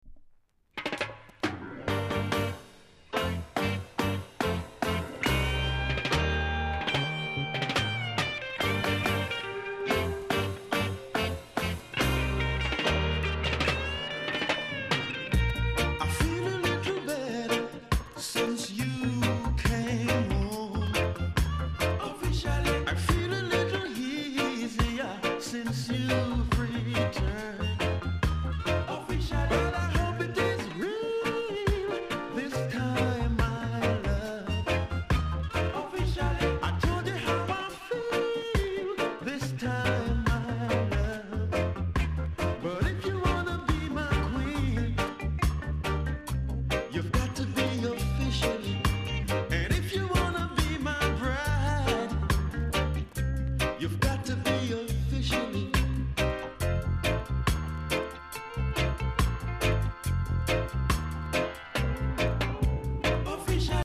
※小さなチリノイズが少しあります。
BIG DISCO 45!!